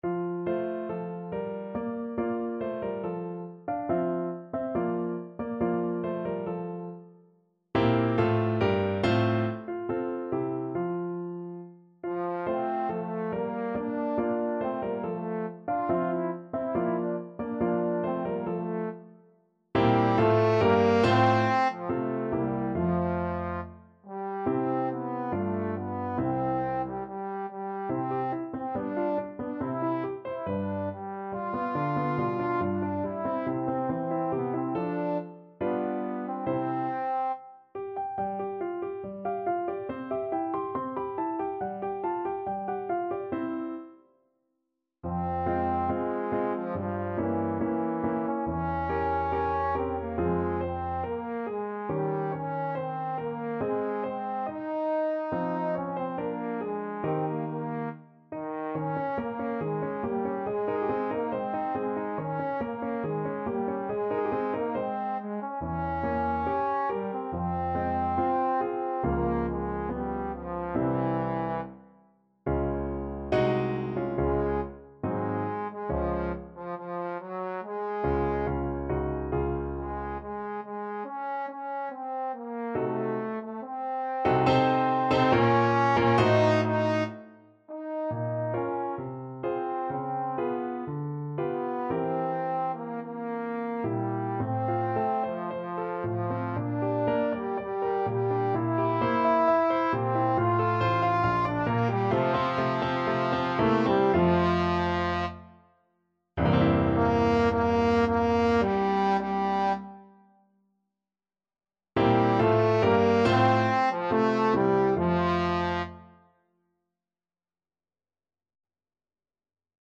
= 70 Allegretto
Eb4-F5
2/4 (View more 2/4 Music)
Classical (View more Classical Trombone Music)